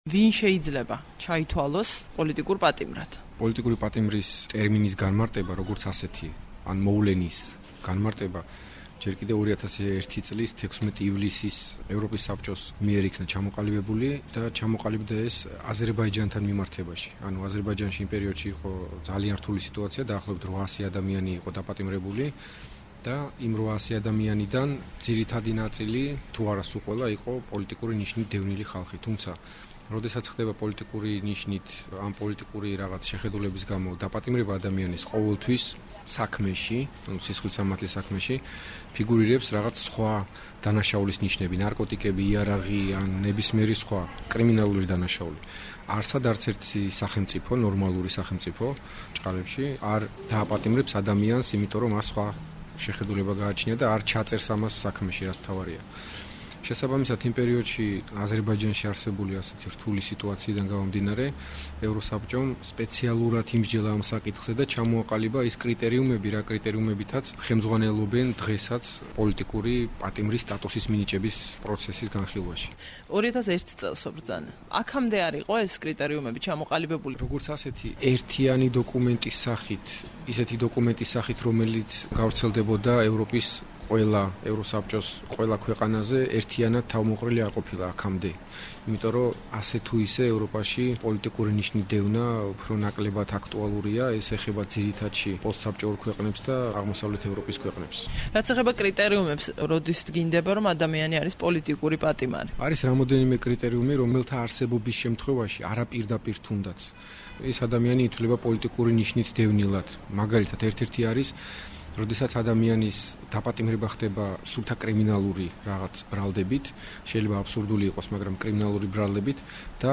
ინტერვიუ